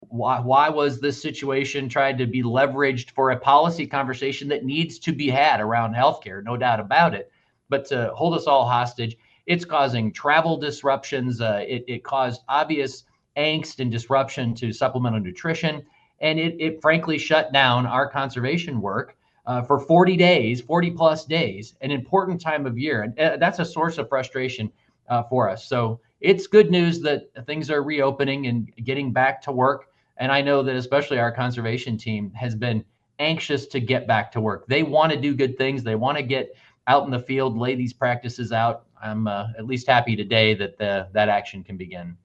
It was a major topic when I sat down for my monthly conversation with Iowa Secretary of Agriculture Mike Naig.